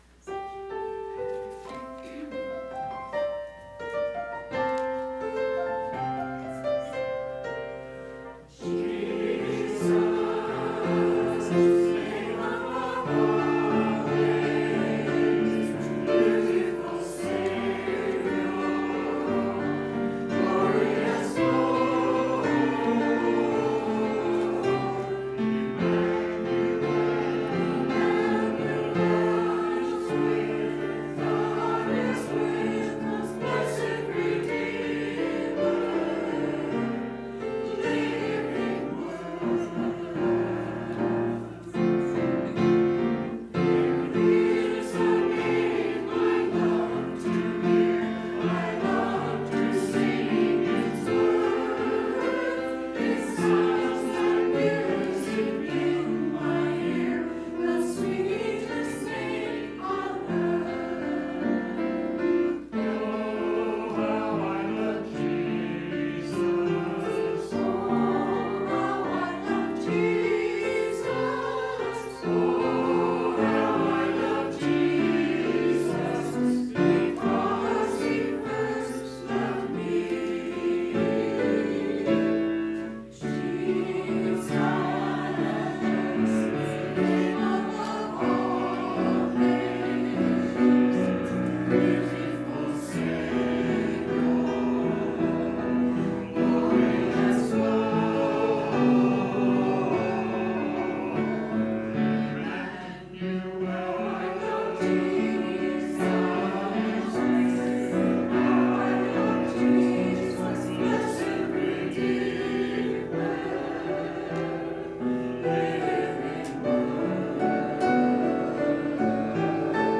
Choir – Jesus Name Above All Names 03.08.20
To hear the church choir praise God with music please click play below.
3.08.20-choir.mp3